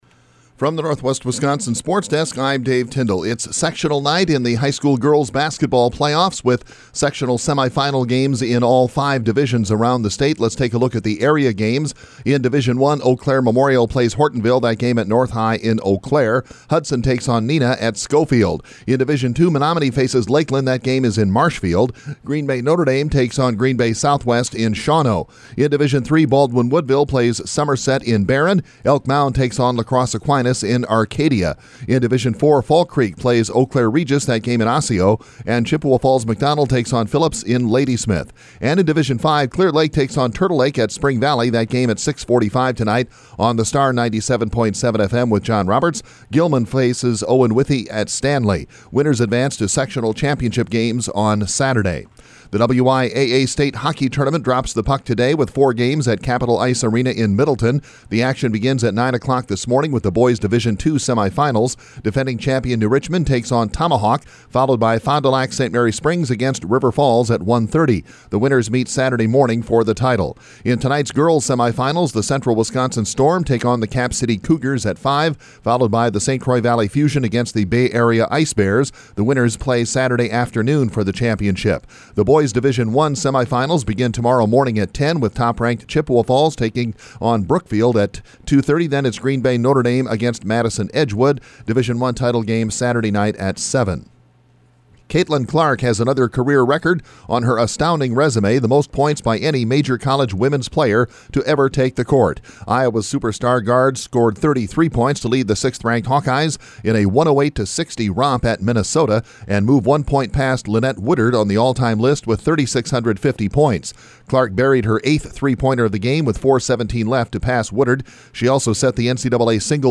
Today’s sportscast from the Northwest Wisconsin Sports Desk.